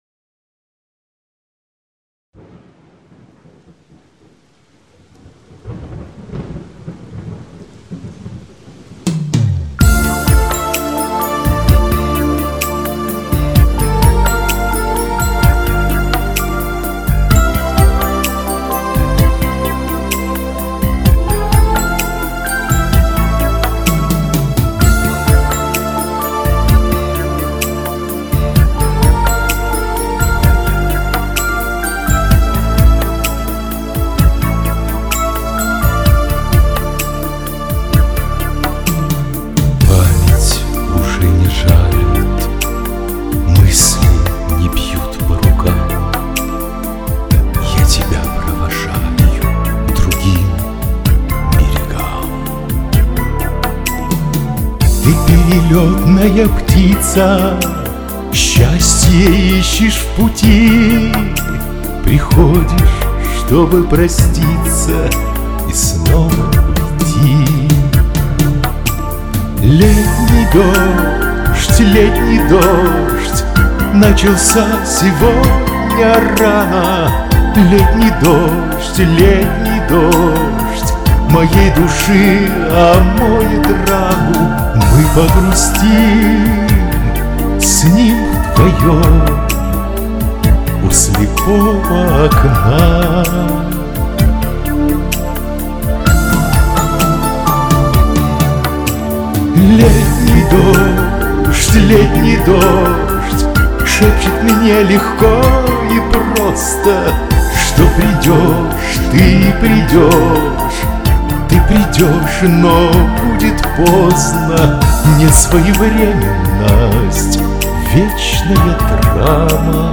в голосе слышится вибрато..но картину это не портит.